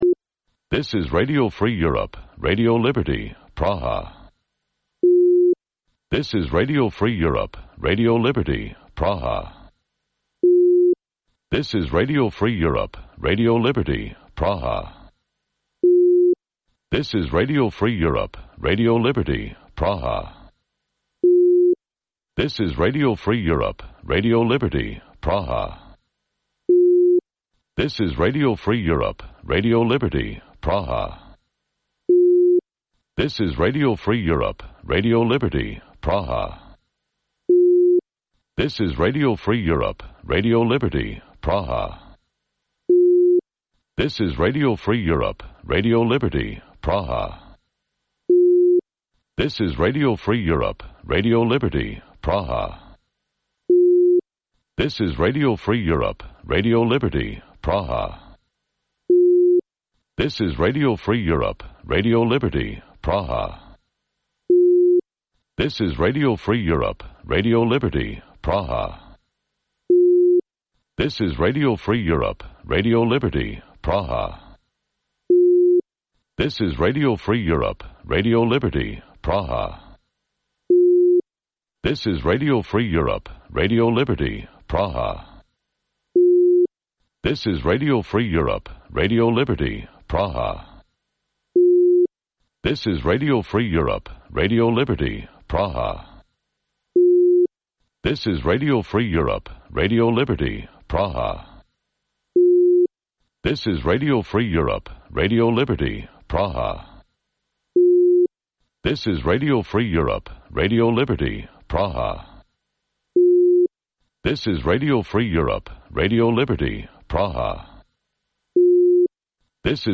Бул үналгы берүү ар күнү Бишкек убакыты боюнча саат 18:00ден 18:30га чейин обого түз чыгат.